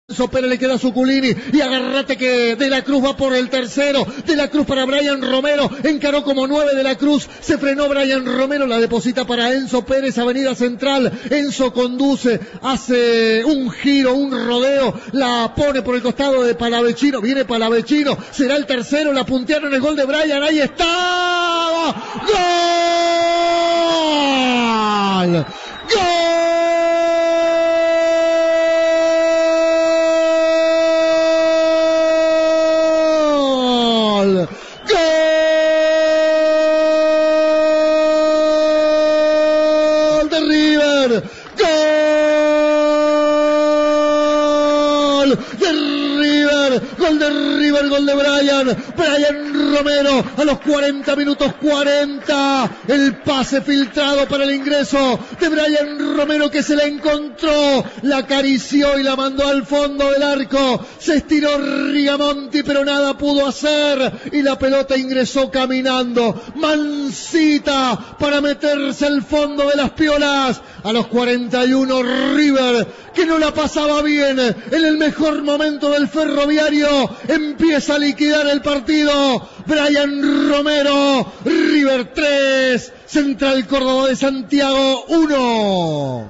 Show de goles en Santiago: reviví el relato de los 4 tantos